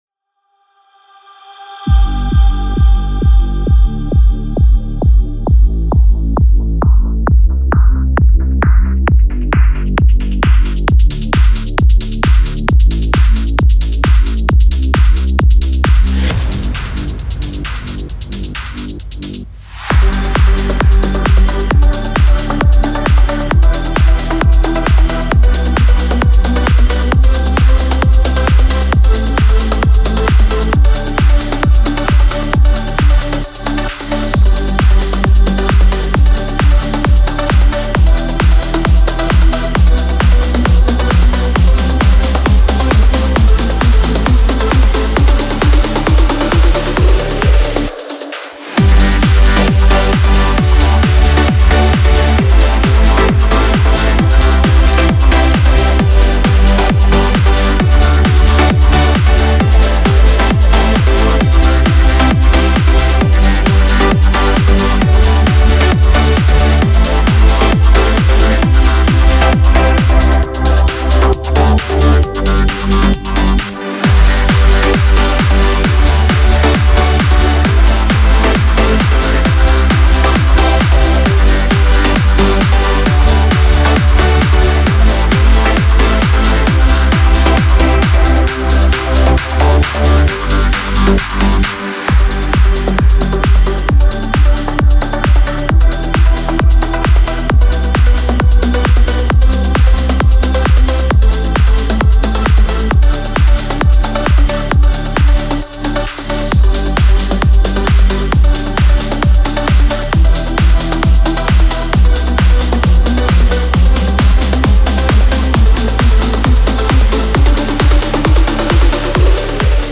Popschlagersong im aktuellen Style.
Hier kannst du kurz ins Playback reinhören.
BPM – 133
Tonart – G-major